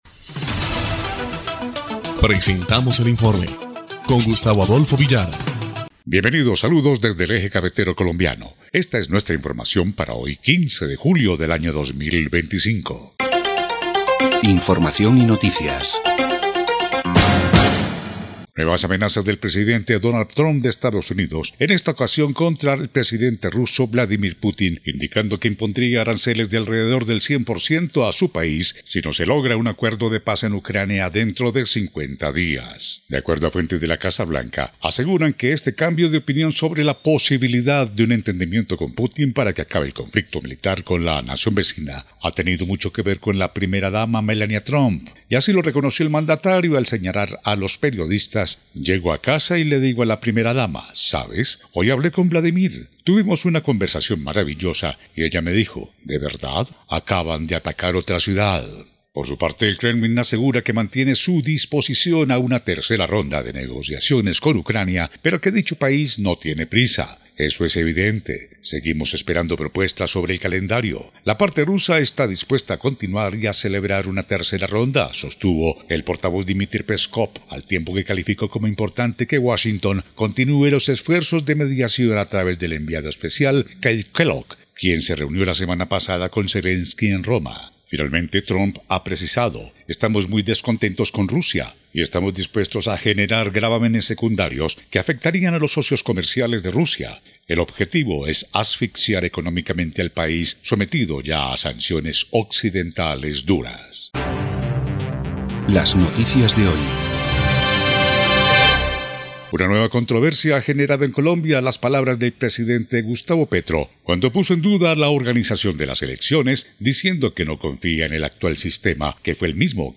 EL INFORME 2° Clip de Noticias del 15 de julio de 2025